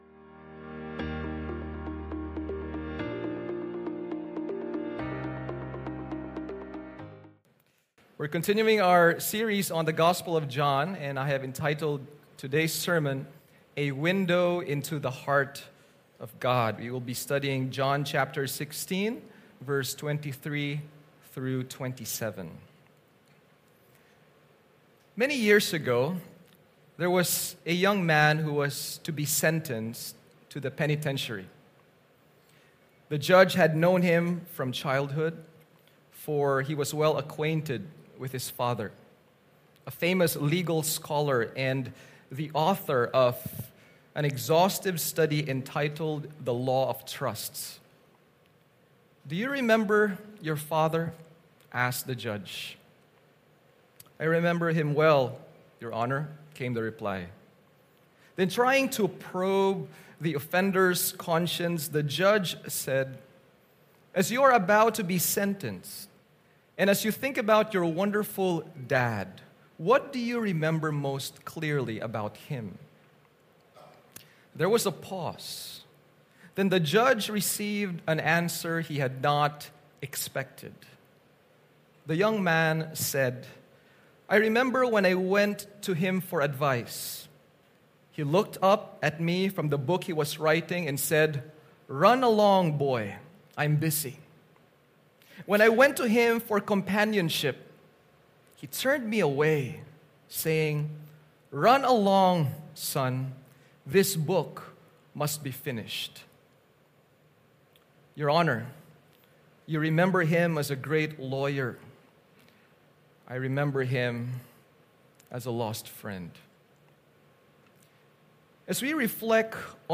English-service-recording-92kbps.mp3